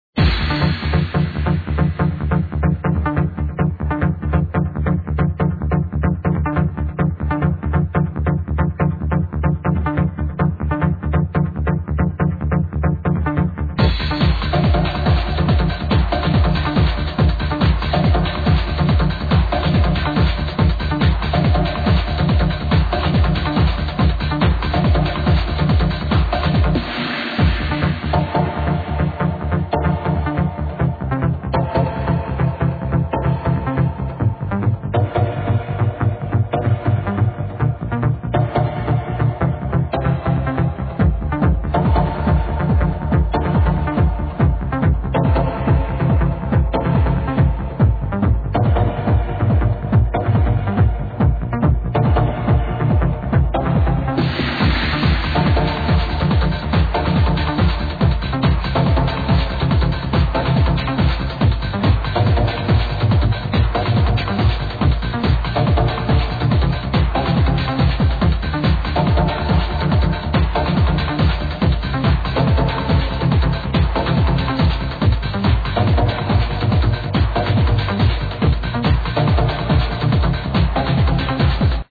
Edited in Cool Edit